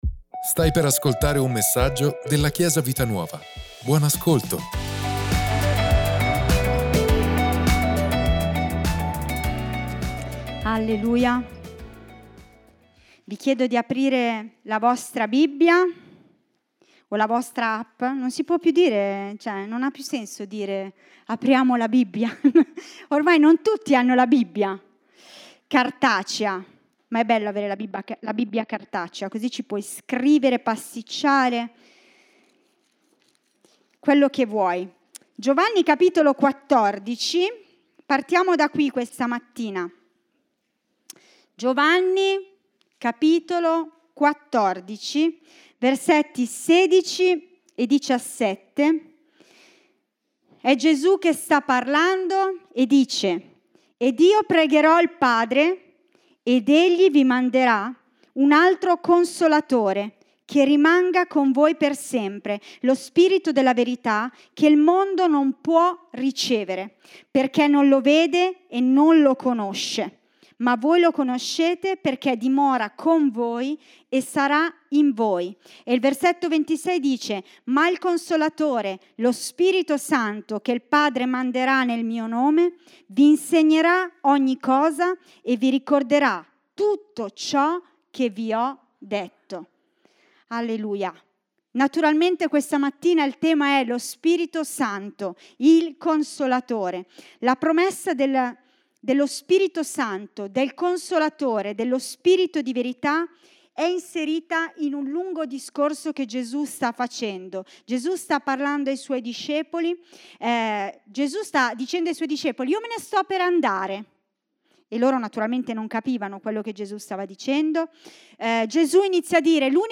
Ascolta la predicazione: Ho bisogno dello Spirito Santo - Chiesa Vita Nuova